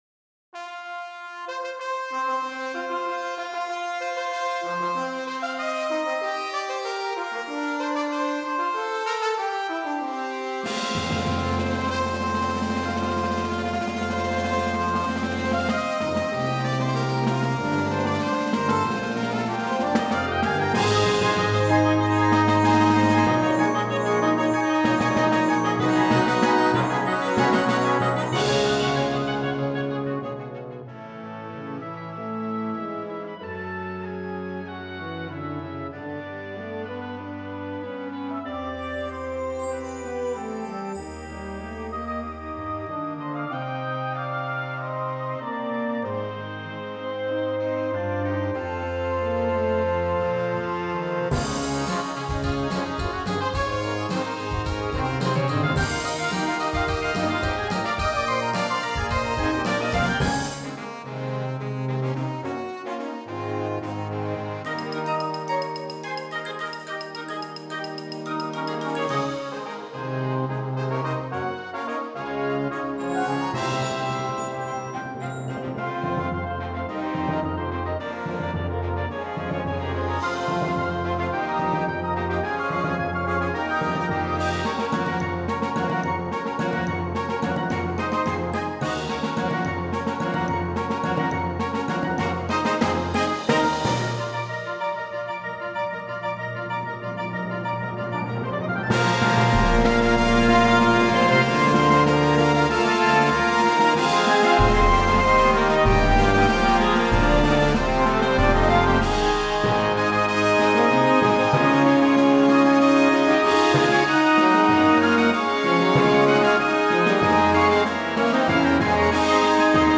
Gattung: Konzertstück
Besetzung: Blasorchester
Eine feierliche Fanfare und eine gehörige Portion Rock.